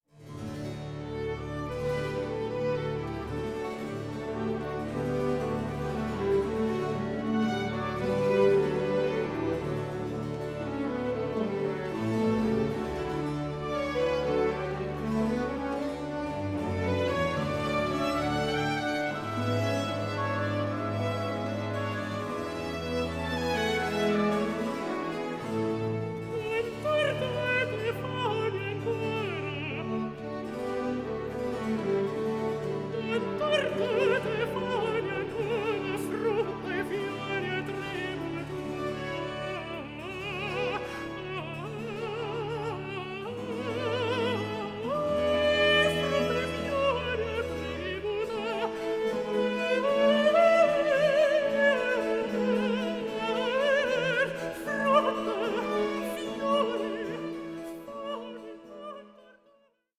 period-instrument group